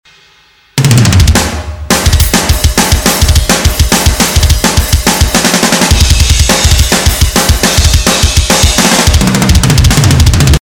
4.-барабан.mp3